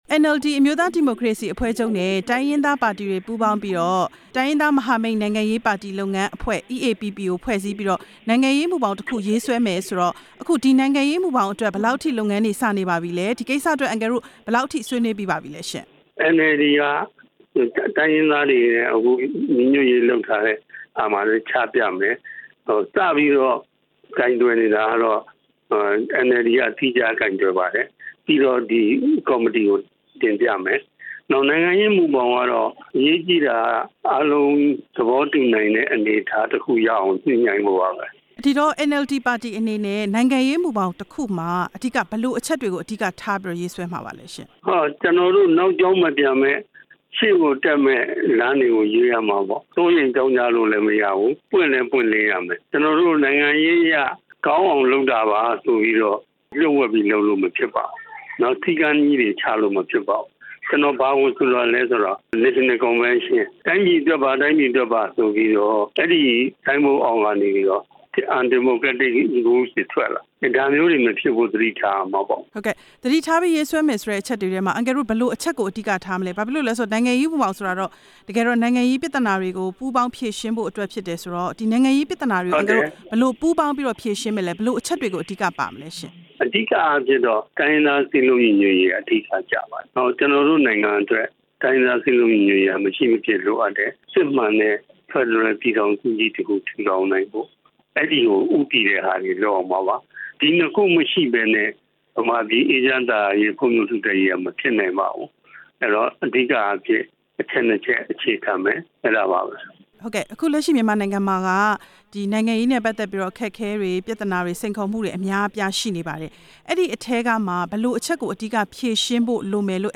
နိုင်ငံရေးမူဘောင်ရေးဆွဲရေး NLD နဲ့ မေးမြန်းချက်